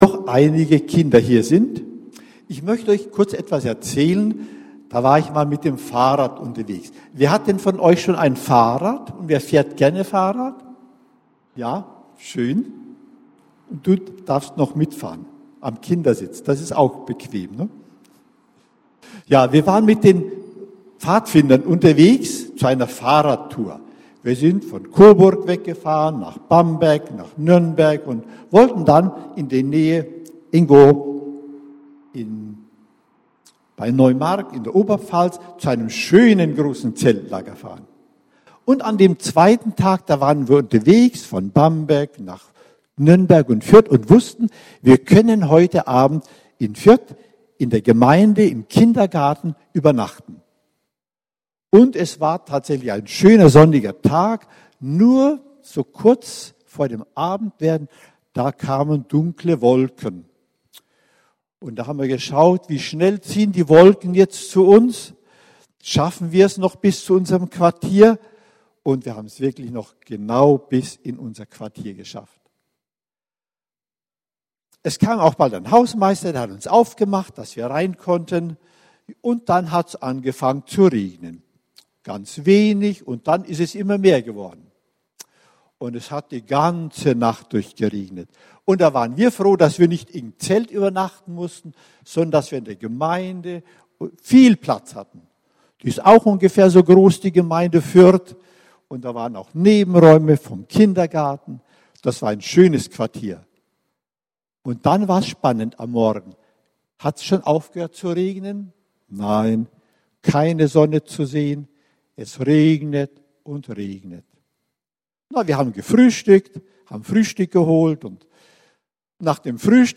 100 Jahre Adventgemeinde Schweinfurt